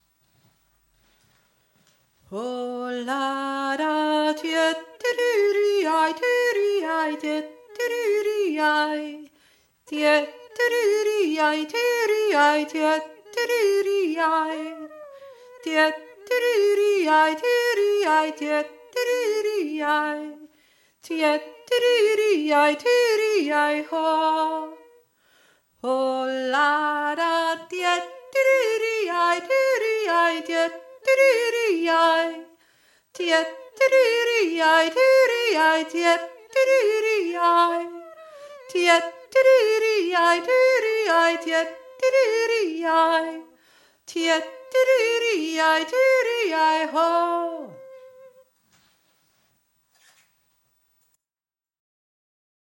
Der Jodler